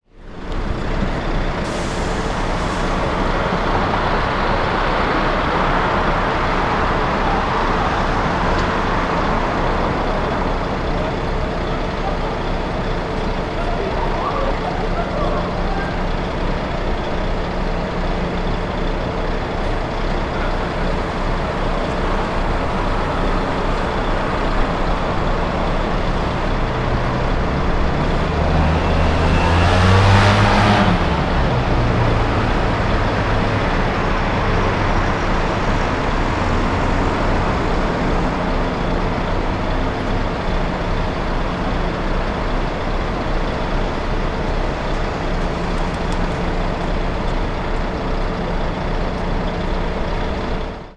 3 cars de tourisme à l'arrêt, moteur au ralenti.
Son enregistré de la passerelle, acoustique de l'enclave... Un véhicule sort du tunnel. Voix humaines.
Outdoor, public
3 bus passerelle mucem-panier.wav